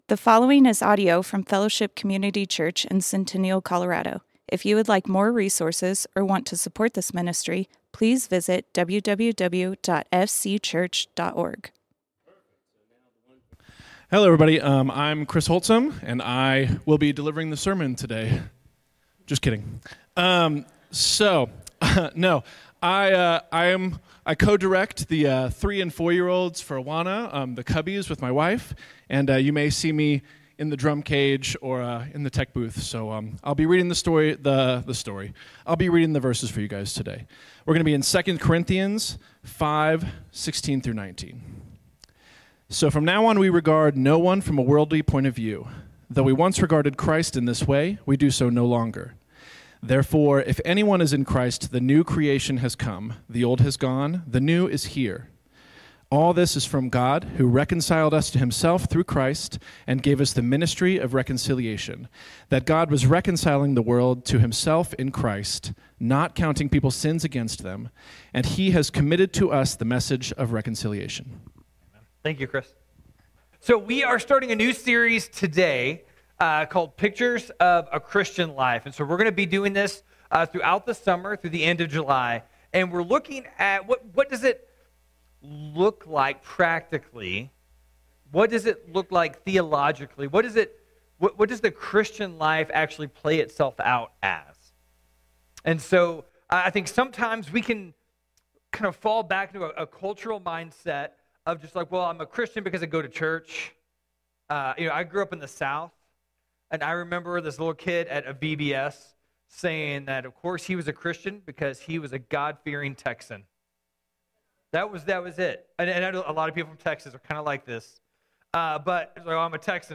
Fellowship Community Church - Sermons A New Creation Play Episode Pause Episode Mute/Unmute Episode Rewind 10 Seconds 1x Fast Forward 30 seconds 00:00 / 36:02 Subscribe Share RSS Feed Share Link Embed